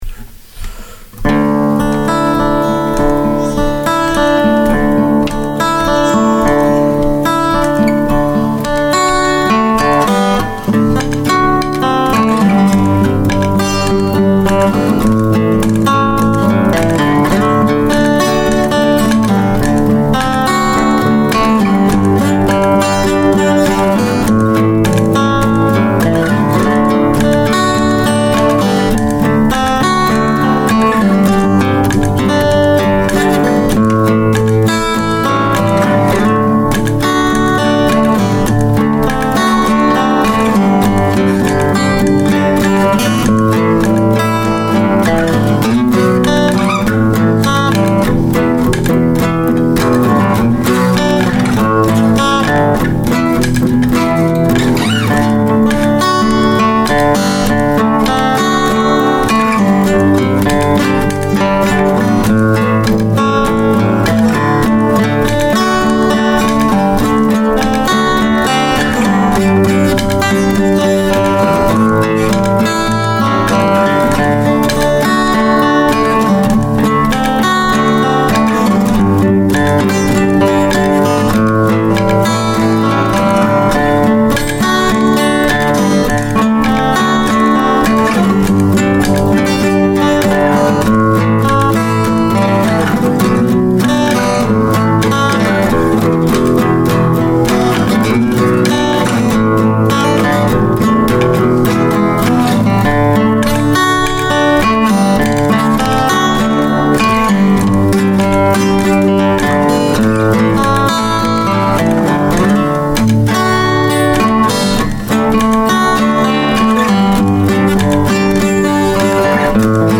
Pretty Against Hate, (instrumental music).